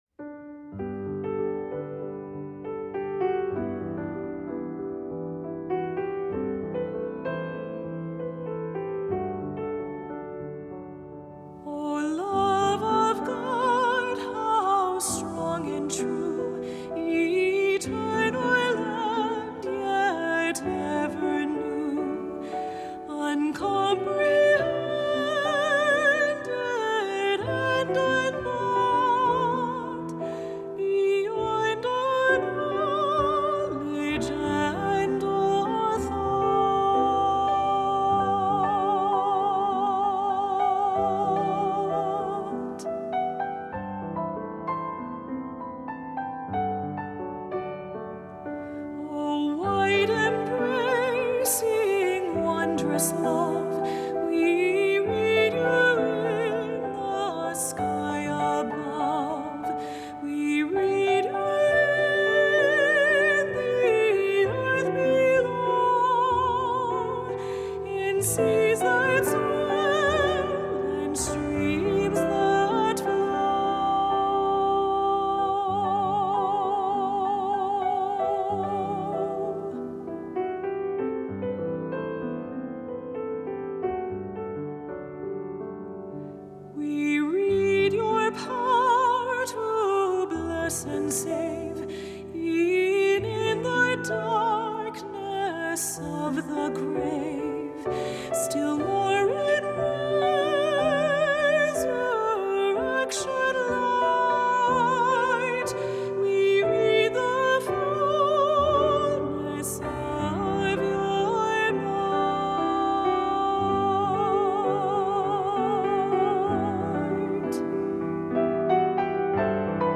Voicing: Medium to Medium-High Voice and Piano